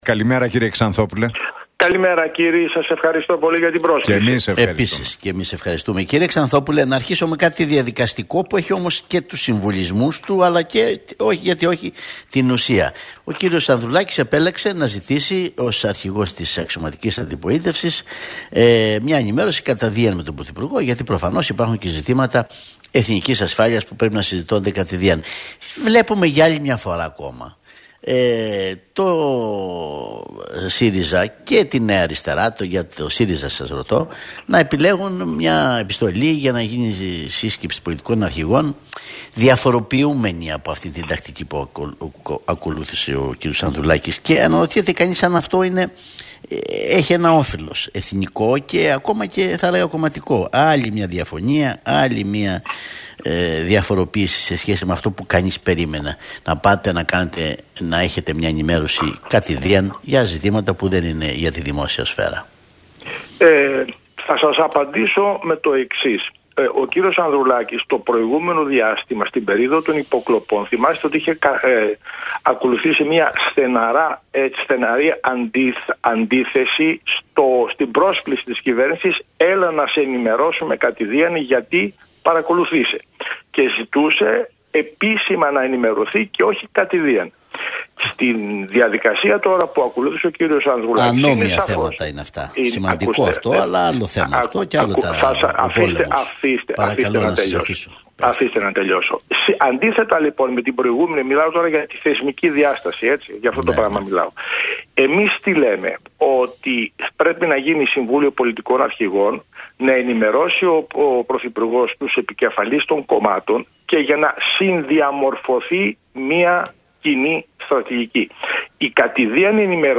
Ξανθόπουλος στον Realfm 97,8: Έχουμε ανάγκη από εθνική συνεννόηση αυτή τη στιγμή - Στον ΣΥΡΙΖΑ έχουμε σταθερή γραμμή